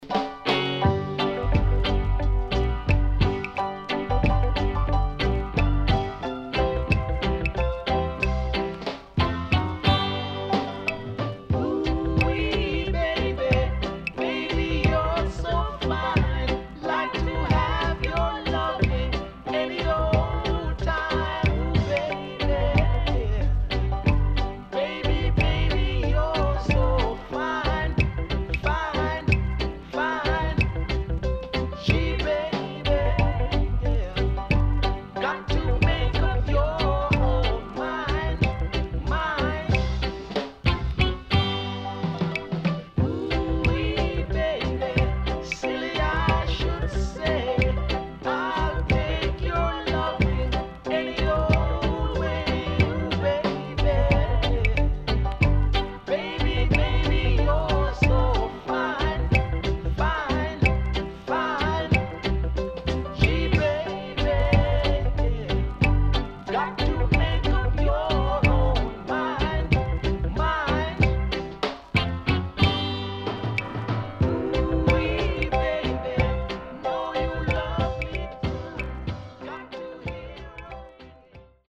CONDITION SIDE A:VG(OK)〜VG+
SIDE A:所々チリノイズがあり、少しプチノイズ入ります。
SIDE B:所々チリノイズがあり、少しプチノイズ入ります。